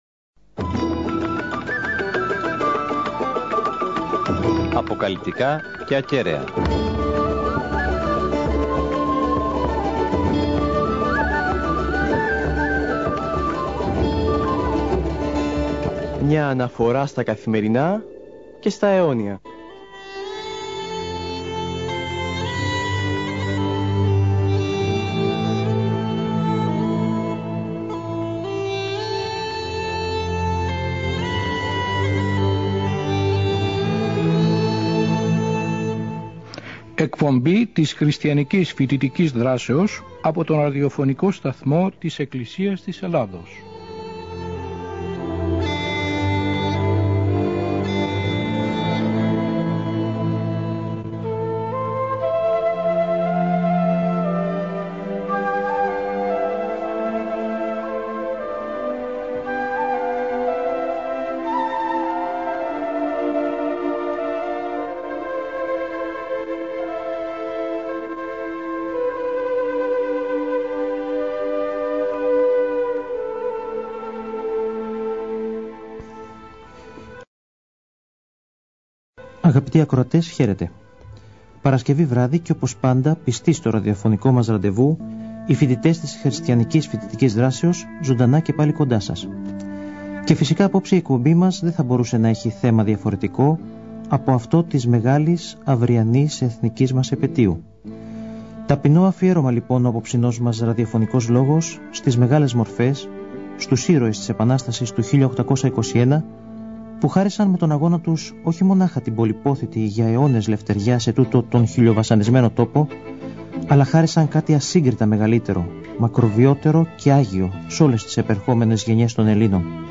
Η εκπομπή μεταδόθηκε ζωντανά από τον σταθμό την Παρασκευή 24 Μαρτίου 2006.